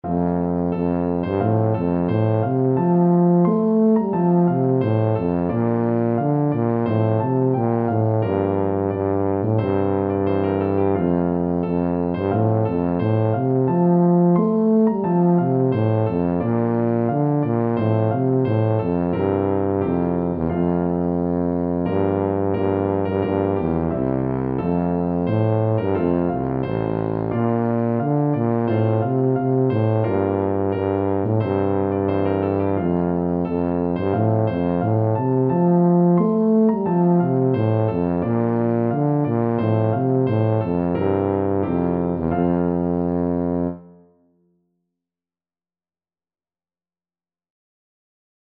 Tuba
4/4 (View more 4/4 Music)
F major (Sounding Pitch) (View more F major Music for Tuba )